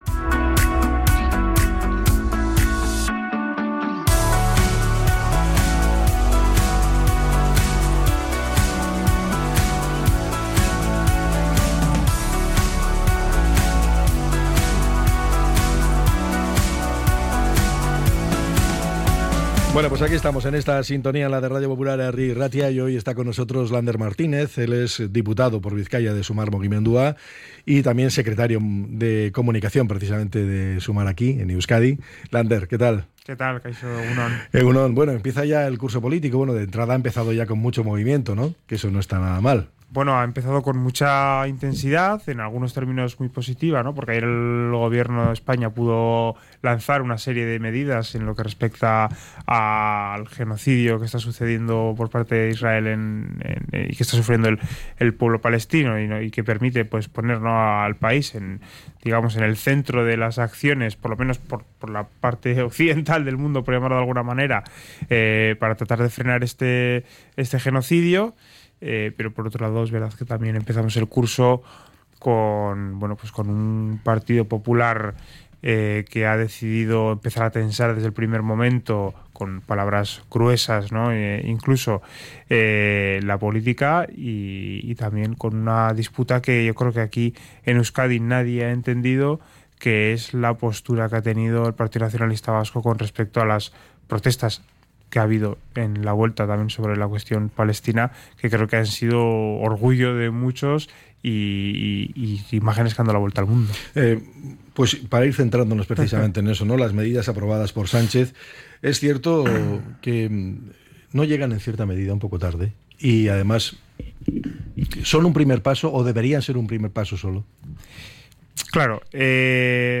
Entrevista con Lander Martínez, diputado por Bizkaia de Sumar Mugimendua